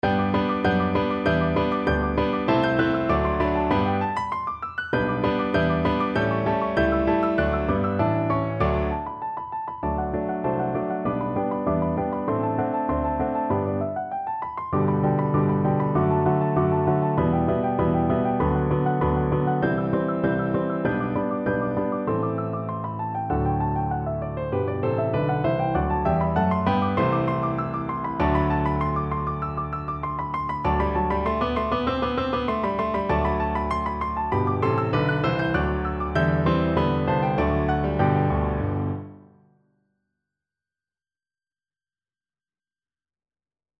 Free Sheet music for Piano Four Hands (Piano Duet)
G major (Sounding Pitch) (View more G major Music for Piano Duet )
4/4 (View more 4/4 Music)
Allegro =196 (View more music marked Allegro)
Classical (View more Classical Piano Duet Music)